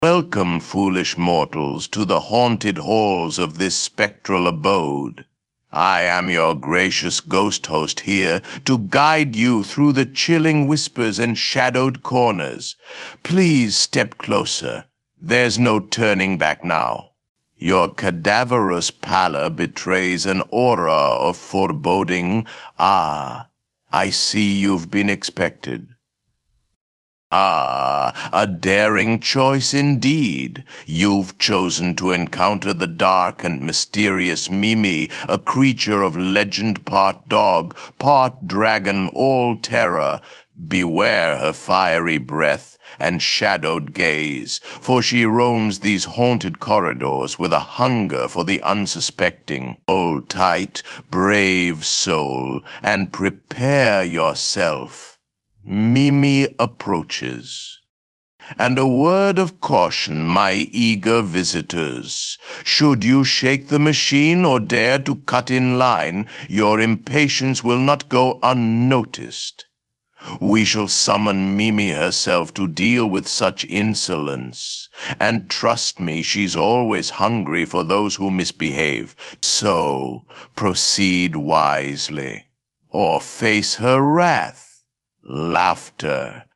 Immerse yourself in our guided audio tour through the companion app site, inspired by the eerie charm of Disney's Haunted Mansion. Scan QR codes to unlock specific narrations and embark on an interactive journey that aims to create a spooky and mysterious atmosphere for trick-or-treaters and passersby.